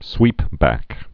(swēpbăk)